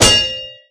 Hammer.ogg